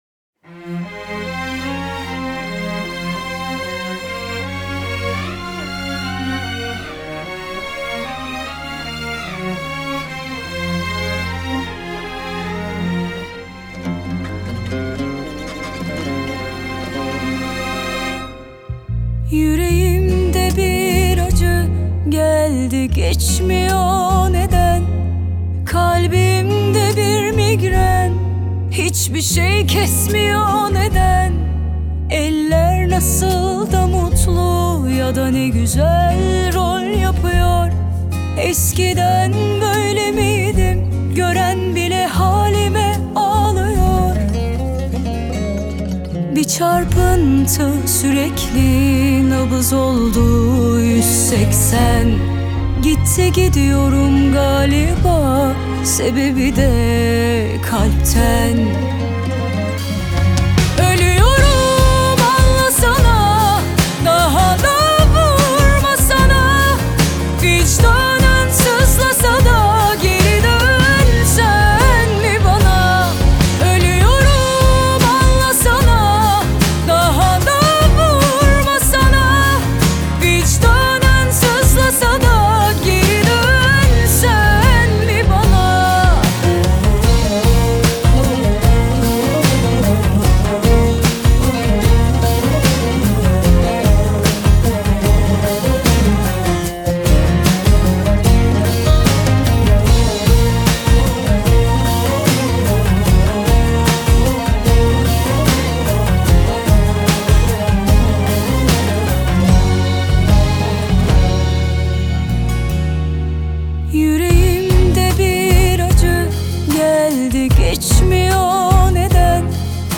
آهنگ ترکیه ای